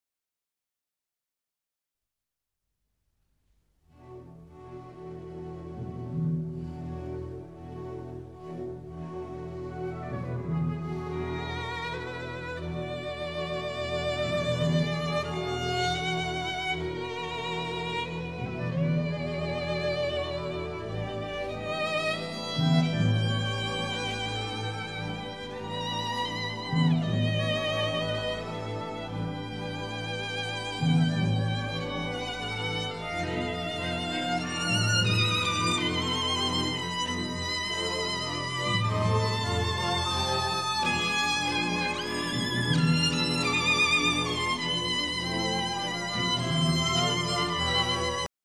Inhalt / Content: I. Allegro moderato, II.